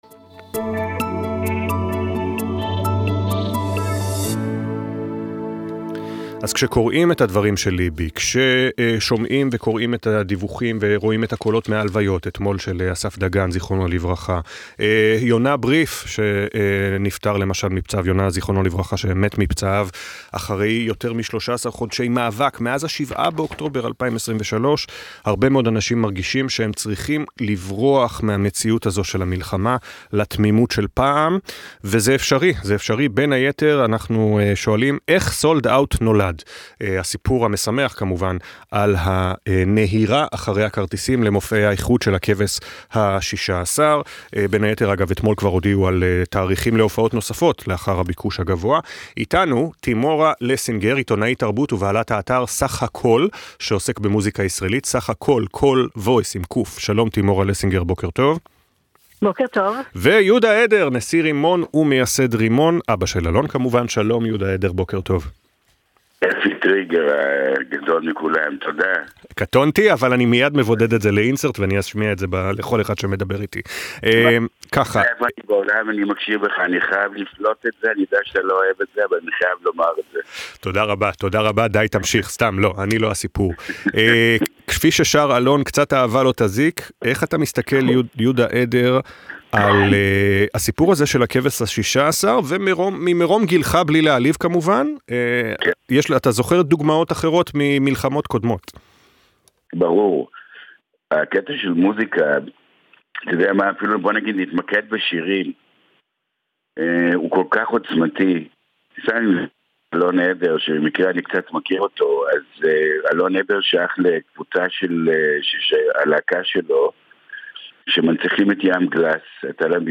רבע לשבע בבוקר,4.12.24. גלי צה"ל